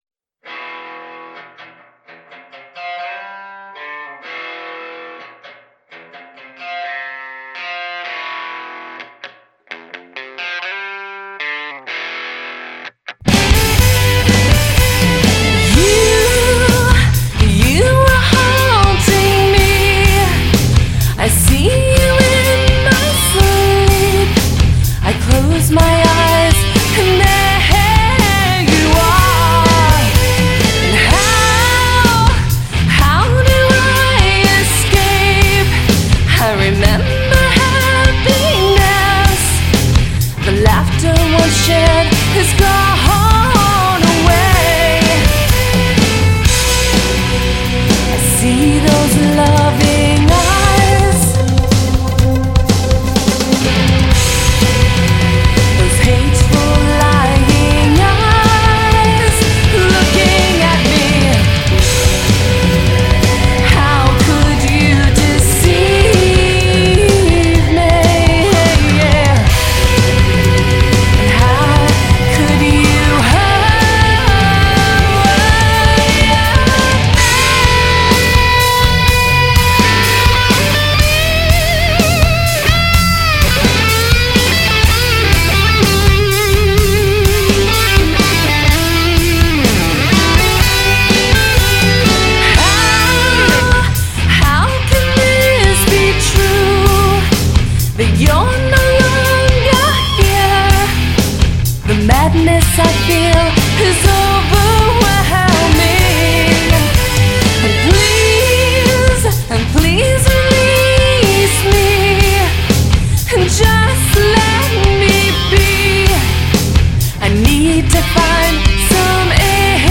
Категория: Хард рок
Progressive rock